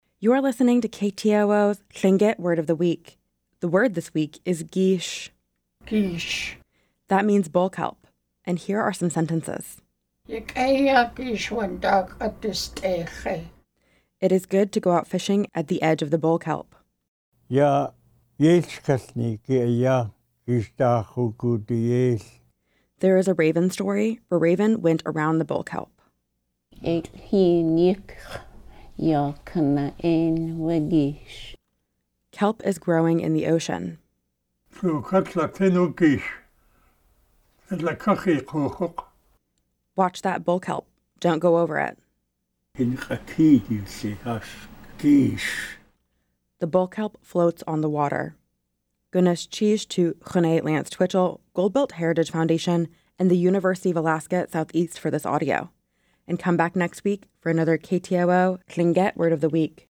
Each week, we feature a Lingít word voiced by master speakers.
You can hear each installment of Lingít Word of the Week on the radio throughout the week.